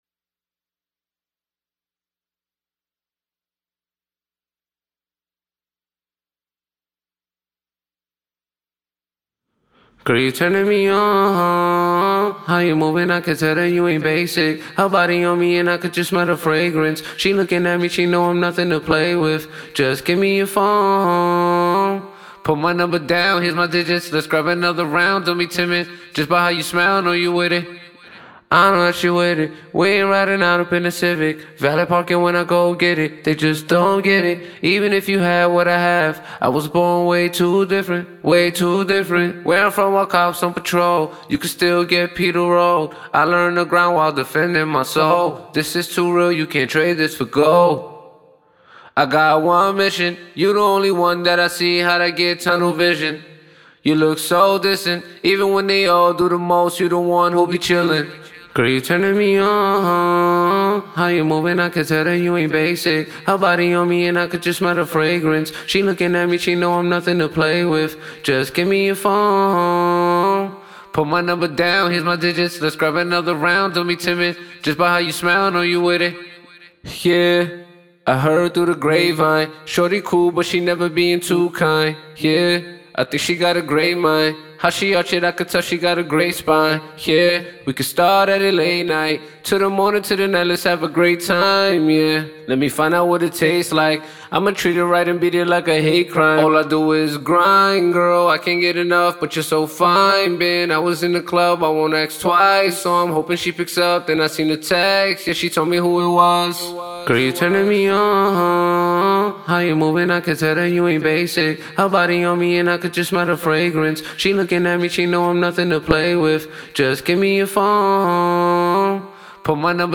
A dope Caribbean vibe track to start your summer of right!